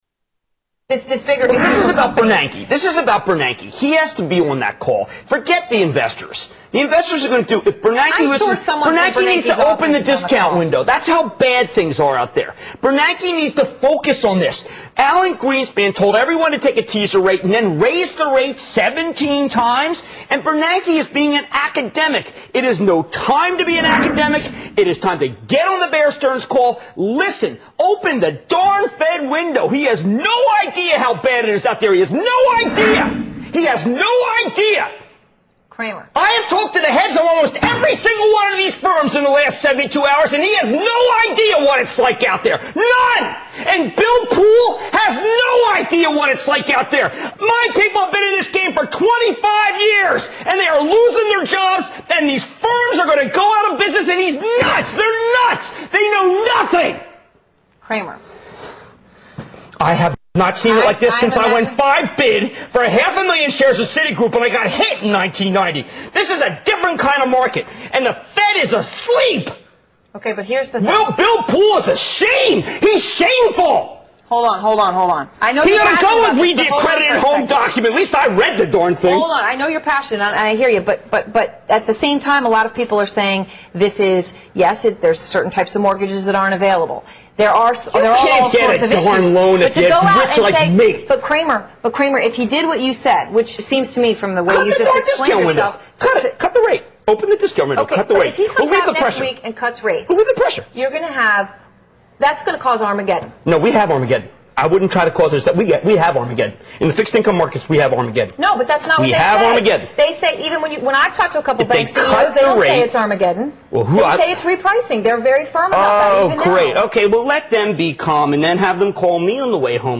Tags: Rick Santelli Jon Stewart Jim Cramer Rants about the US economy Economic rants